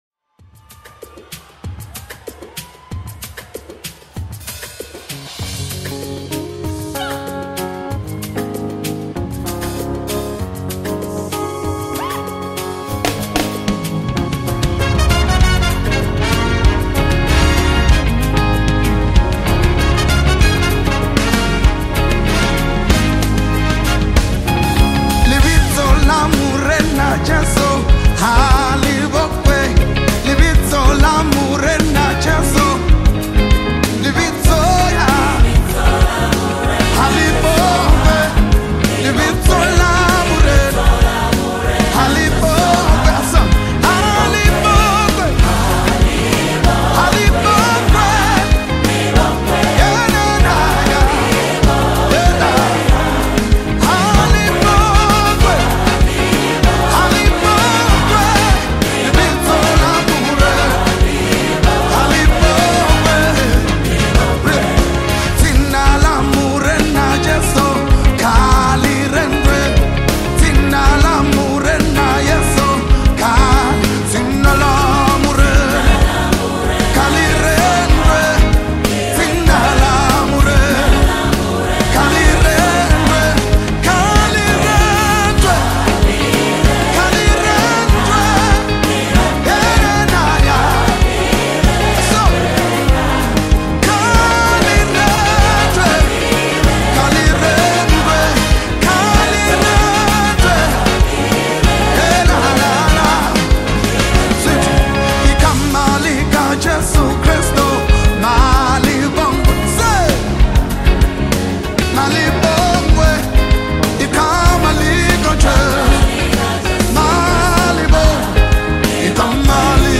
a smooth and engaging tune
It delivers a sound that feels both fresh and consistent.